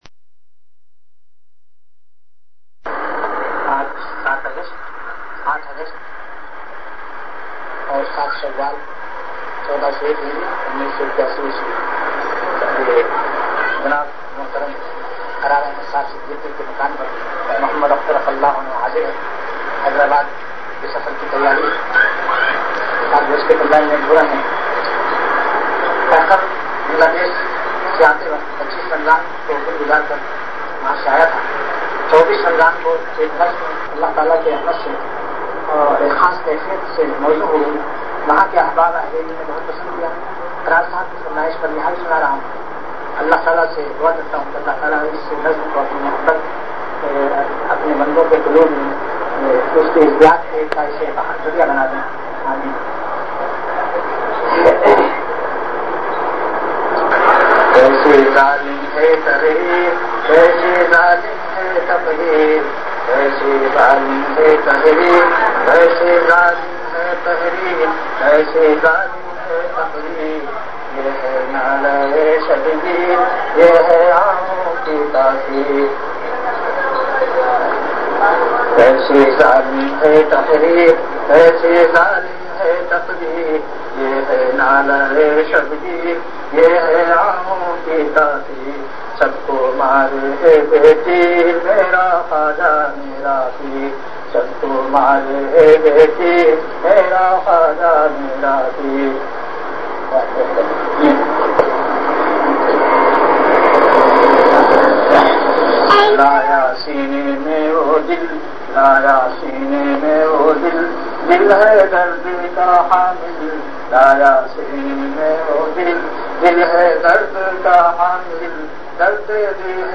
Delivered at Home.
Event / Time After Isha Prayer